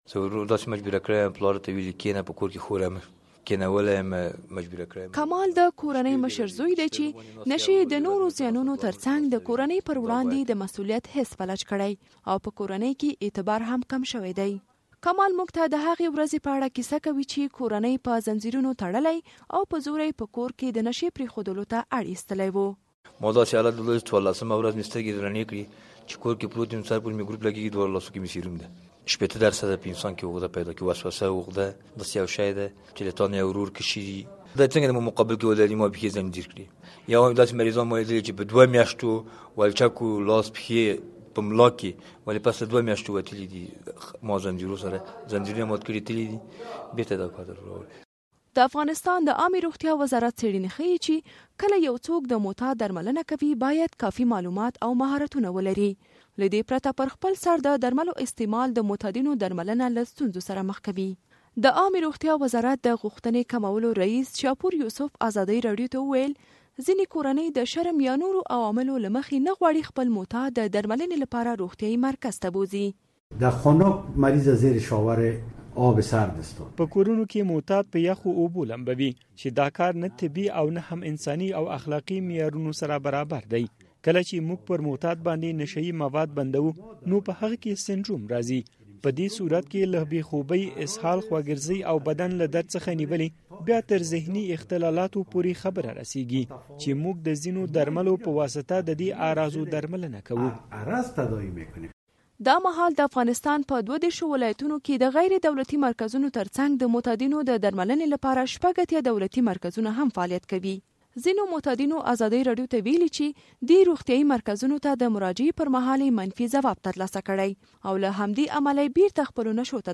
غزیز راپور